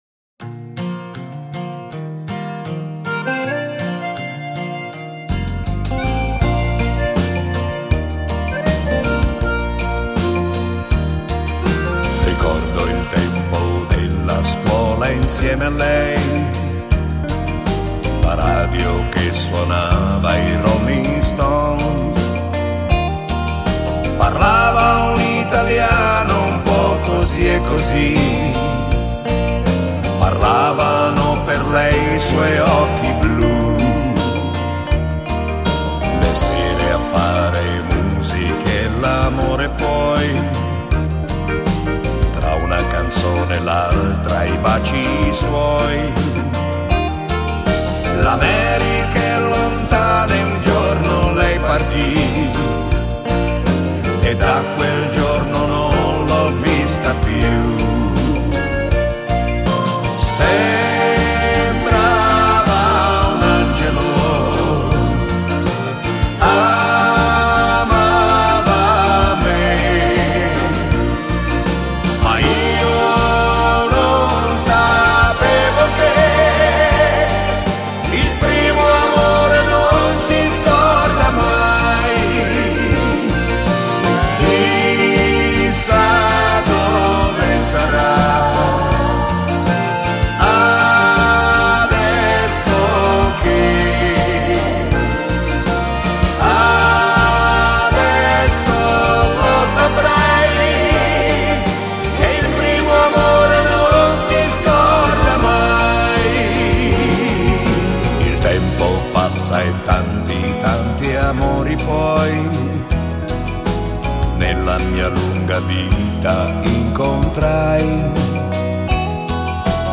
Genere: Moderato ballato